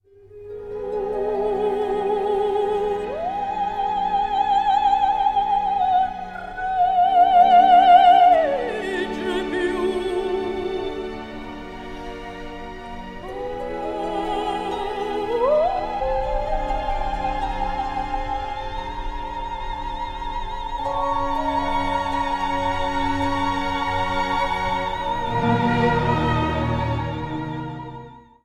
Duración: 9 compases 4/4.
Clásico